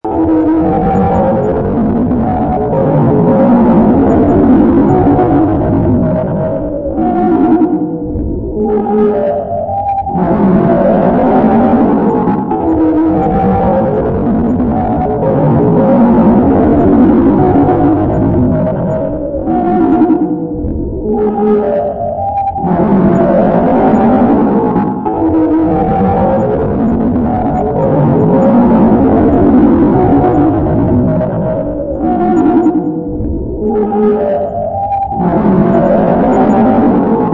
Sound Effects
Weird Noise